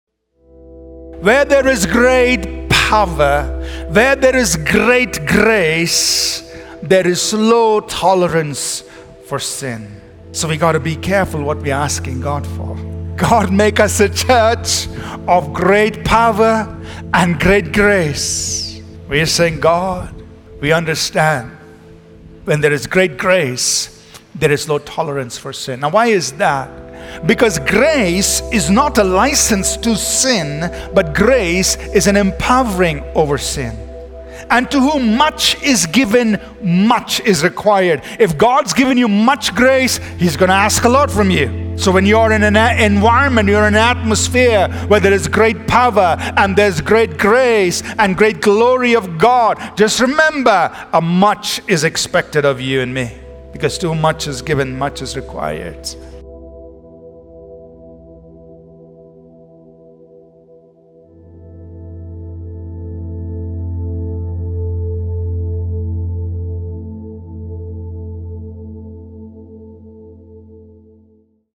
Sermon Keypoints Podcast - Signs and wonders | Free Listening on Podbean App